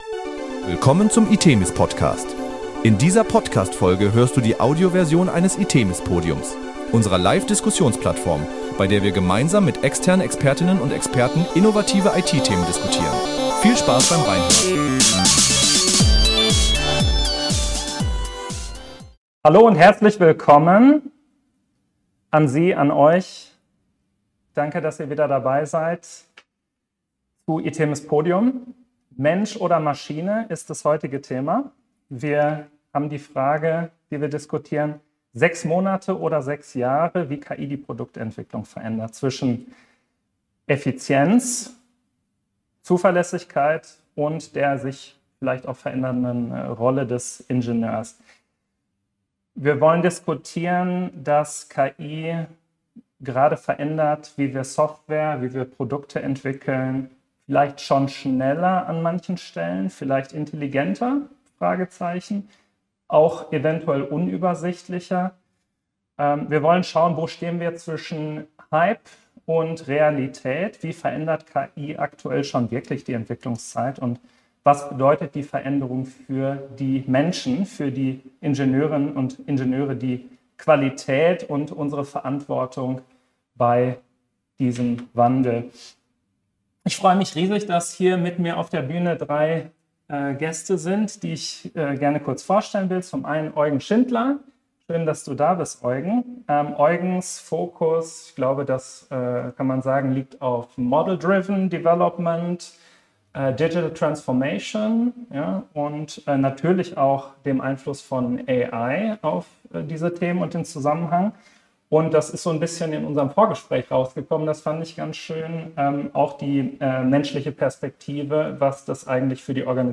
In dieser Folge des itemis PODCASTS hört ihr die Audioversion des itemis PODIUMS.
Im Gespräch diskutieren unsere Expert:innen, wie realistisch drastische Verkürzungen von Entwicklungszyklen sind und wo die technologischen Grenzen liegen.